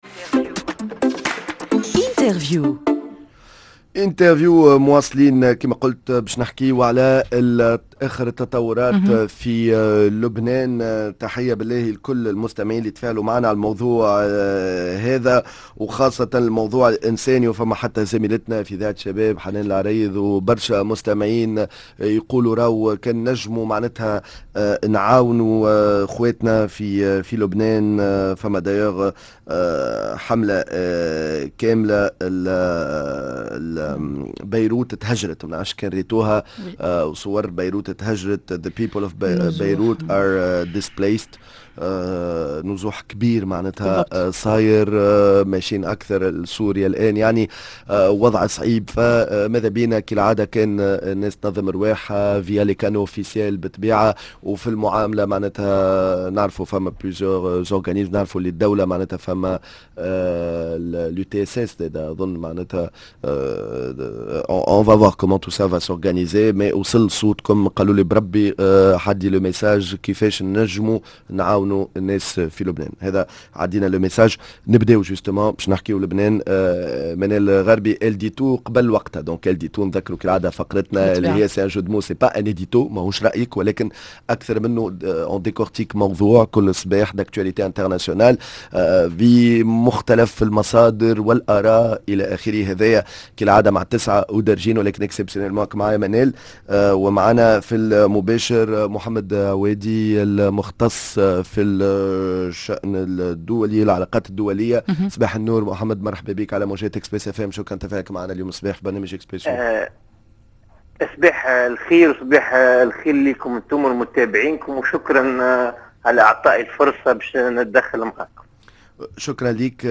ماذا يحدث في لب.نا.ن بعد إغت.ي.ال قيادات حزب ا.لله؟! كل التفاصيل مع المحلل السياسي